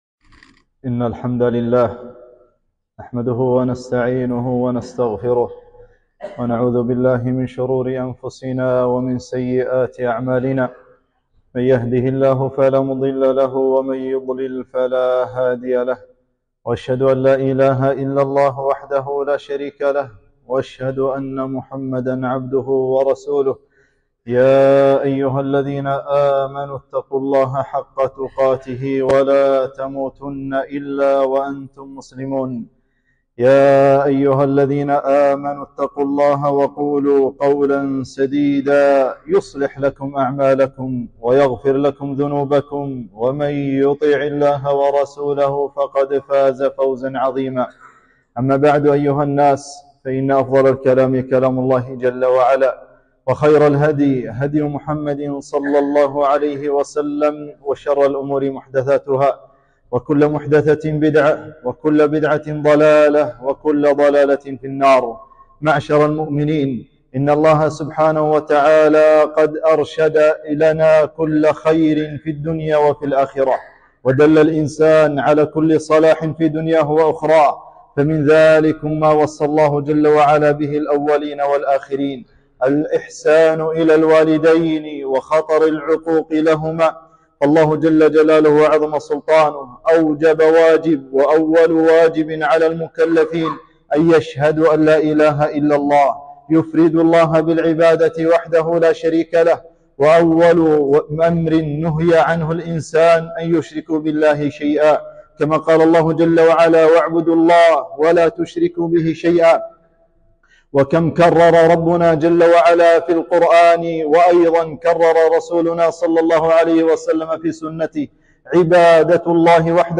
خطبة - الوالدين برهمها وخطر عقوقهما